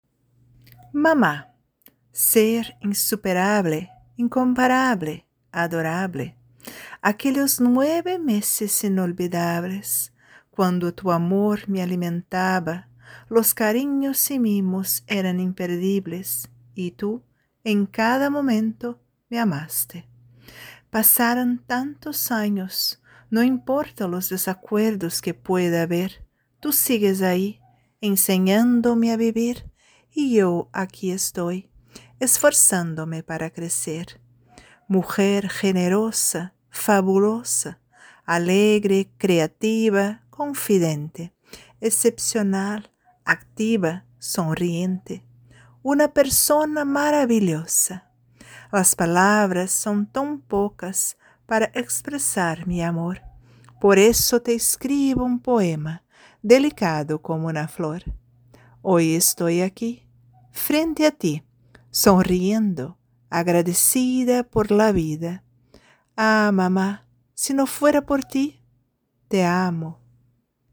Canción de cuna en el bosque